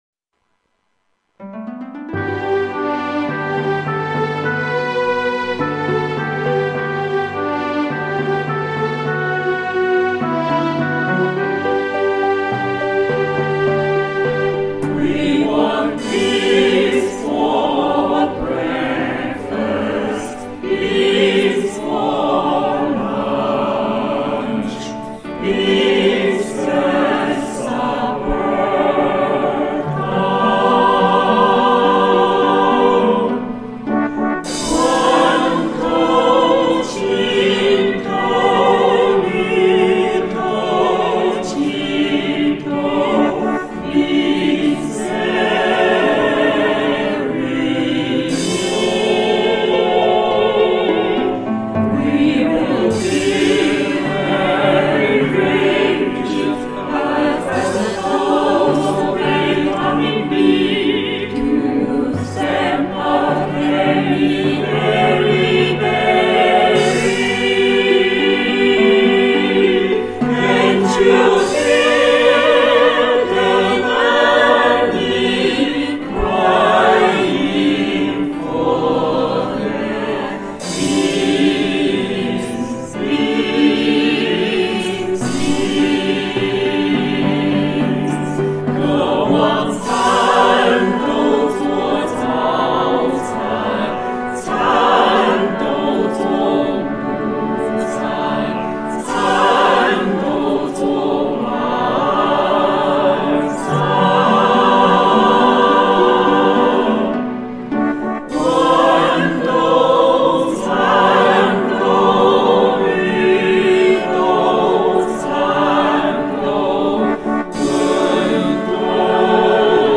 【十年山庆】《南京难民合唱》- 海内外歌友演唱
很高兴有多位海内外朋友加入这个网络合唱！